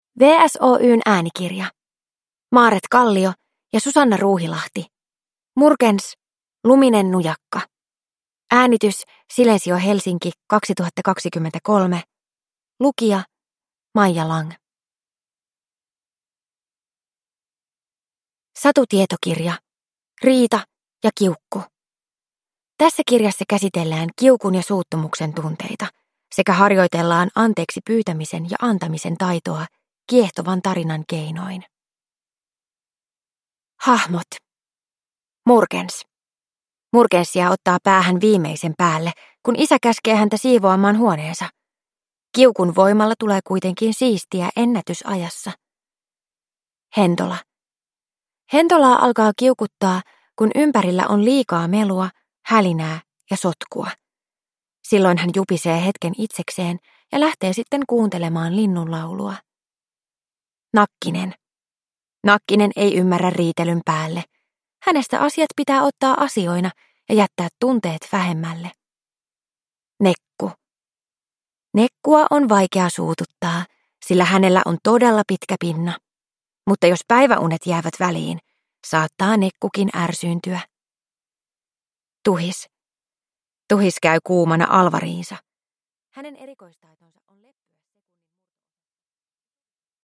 Murkens: Luminen nujakka – Ljudbok – Laddas ner